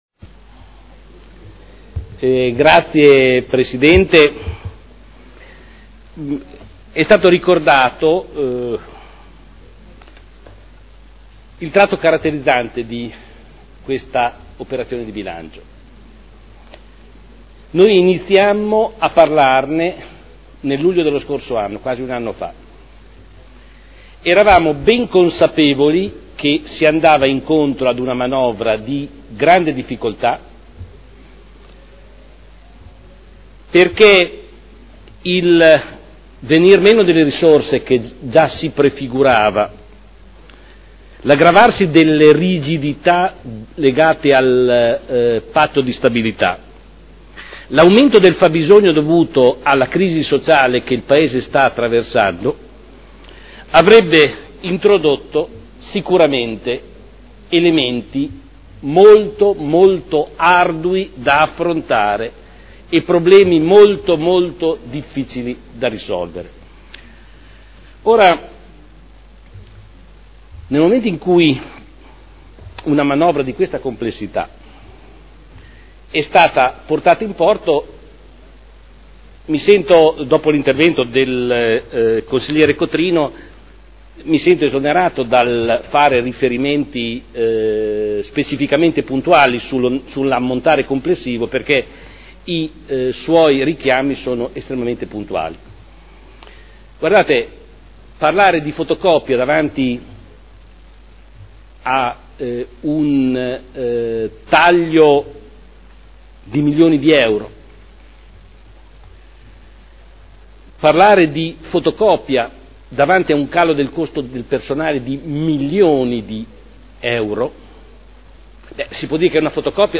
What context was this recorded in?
Seduta dell'11 giugno Replica su: relazione Previsionale e Programmatica - Bilancio Pluriennale 2012/2014 - Bilancio Preventivo per l'esercizio finanziario 2012 - Programma triennale dei lavori pubblici 2012/2014 – Approvazione